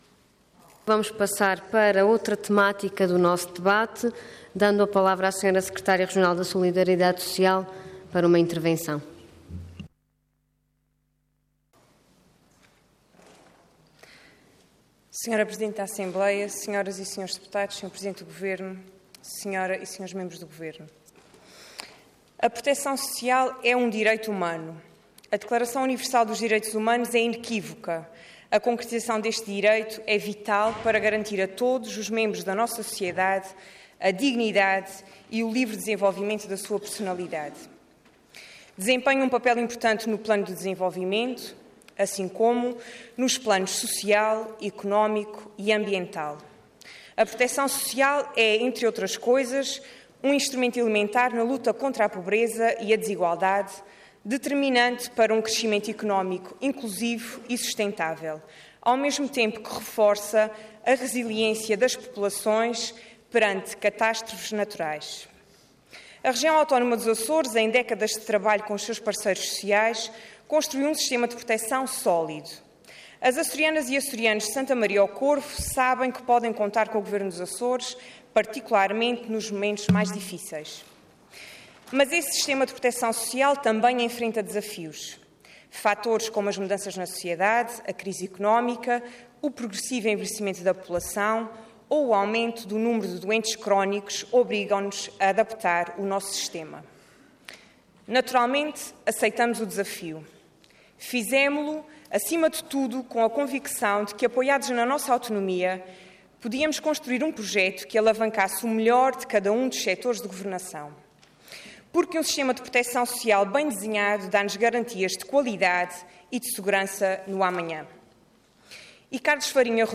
Detalhe de vídeo 24 de novembro de 2015 Download áudio Download vídeo X Legislatura Plano e Orçamento para 2016 - Solidariedade Social Intervenção Proposta de Decreto Leg. Orador Andreia Costa Cargo Secretária Regional da Solidariedade Social Entidade Governo